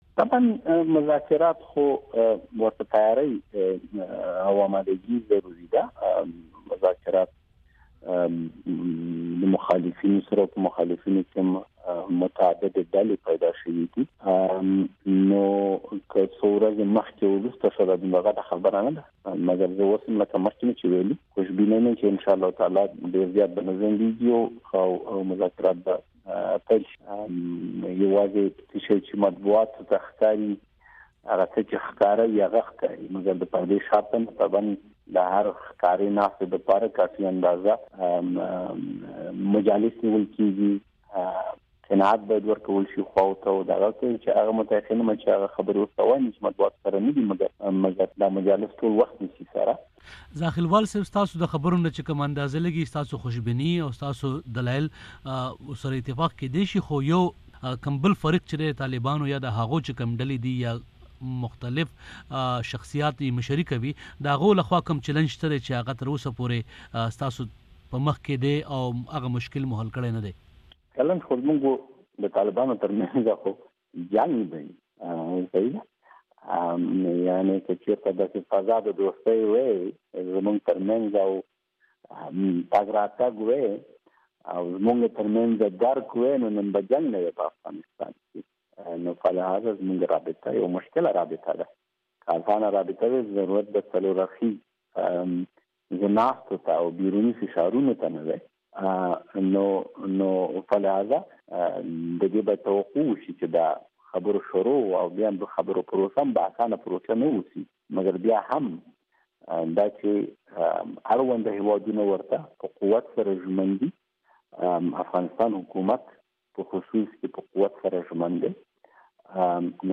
په پاکستان کې د افغانستان سفير حضرت عمر زاخېلوال ويلي دي چې د طالبانو سره د مزاکراتو لړۍ ودریدلې نه ده او د پردې شاته خبرې روانې دي - ښاغلي زاخېلوال د ډیوه ریډیو سره په یوه ځانگړې مرکه کې ويلي دي چې که دا مزا کرات څو ورځې مخکې وروستو شول نو دا څه غټه خبره نه ده - ښاغلی زاخېلوال واېي "زه خوش بينه یمه چې دا مزاکرات به ډیر زیات نه ځنډيږي او ډیر زر به پیل شي" ښاغلی زاخېلوال زیاتوي چې مطبوعاتو ته یواځې هغه څه ښکاري چې په ډاگه وي خو واېي چې ډیر څه د پردې شاته هم روان وي چې وخت او قناعت غواړي –